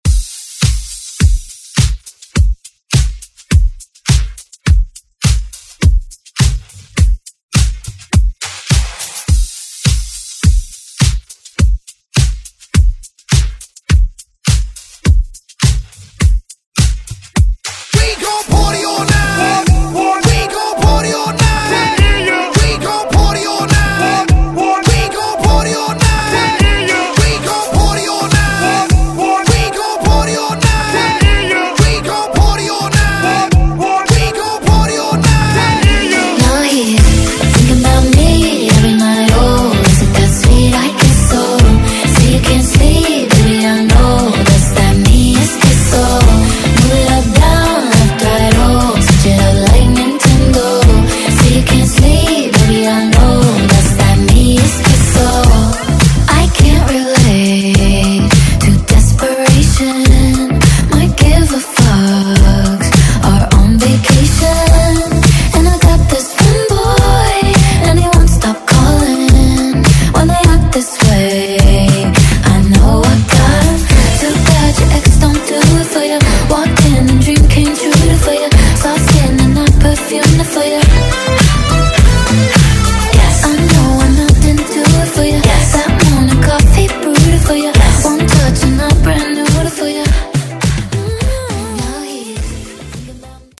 Genres: RE-DRUM , TOP40 Version: Clean BPM: 104 Time